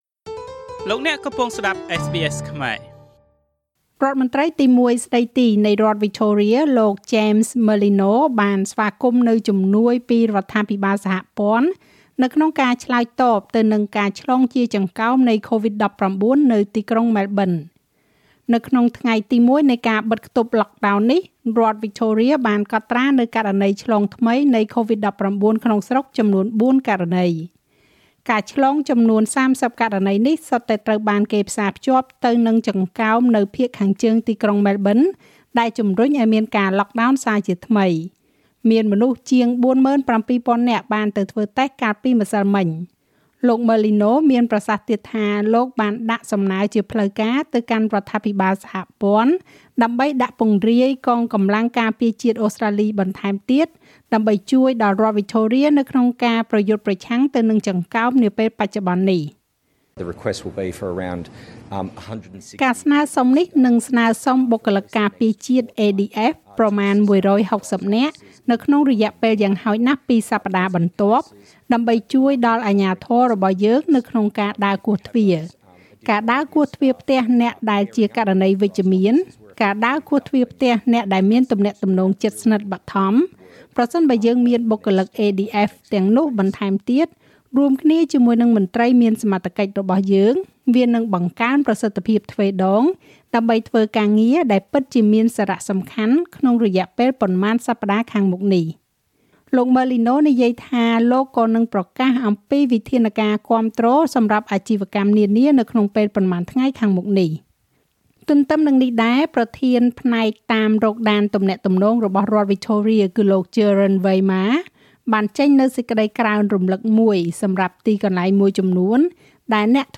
ស្តាប់មាតិកាព័ត៌មានចុងក្រោយបង្អស់ក្នុងប្រទេសអូស្រ្តាលីពីវិទ្យុSBSខ្មែរ។